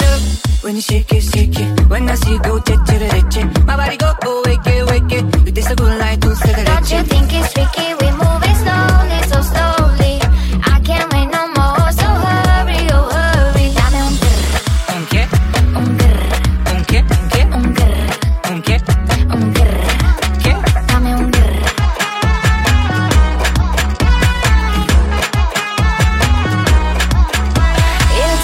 Genere: pop,dance,afrobeat,house,latin,edm,remixhit